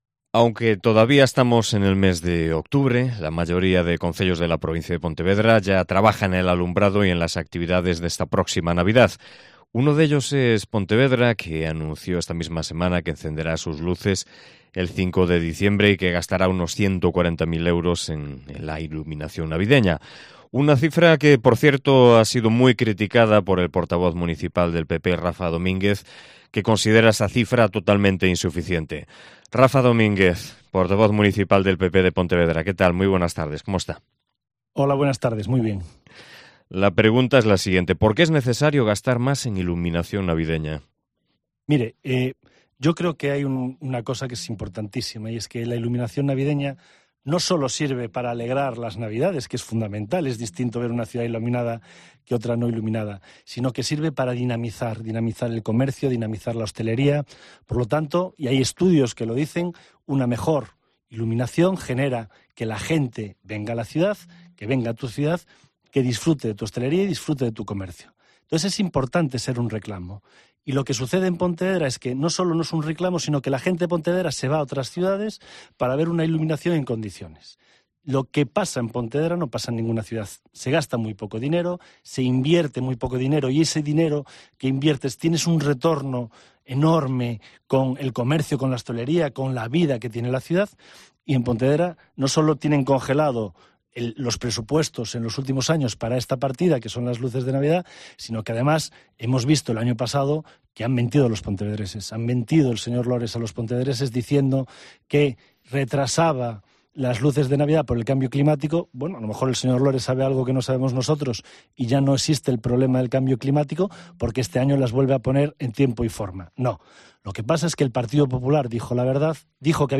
AUDIO: Entrevista patrocinada por el Grupo Municipal del Partido Popular